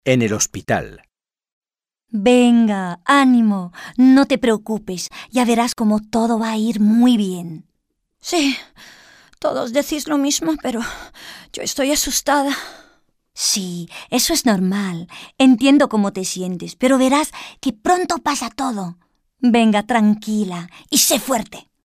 Dialogue - En el hospital